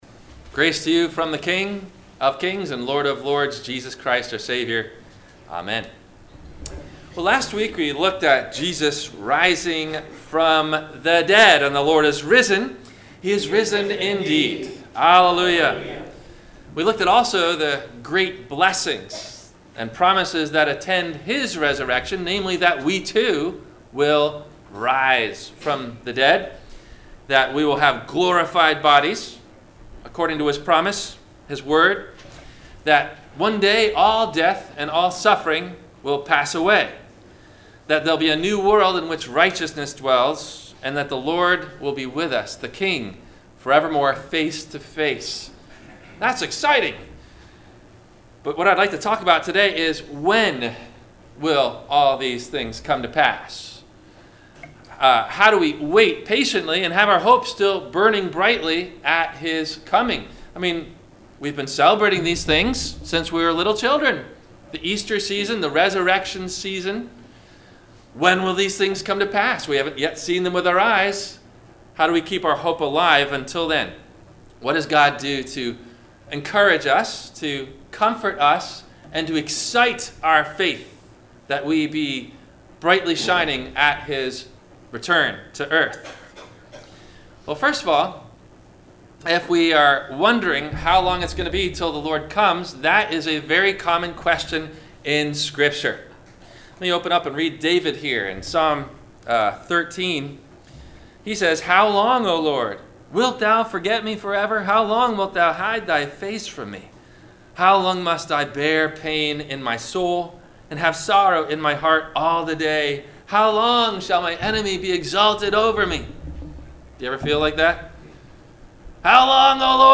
- Sermon - April 28 2019 - Christ Lutheran Cape Canaveral